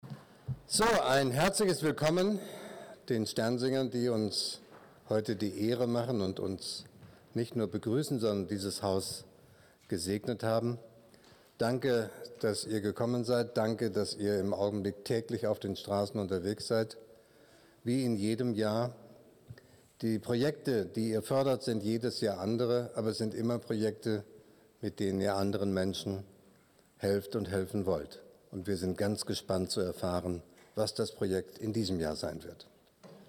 Empfang beim Bundespräsidenten
Willkommen am Schloss: Bundespräsident Frank-Walter Steinmeier freute sich über den Besuch der Sternsinger aus der Pfarrei St. Ewaldi in Dortmund-Aplerbeck (Erzbistum Paderborn).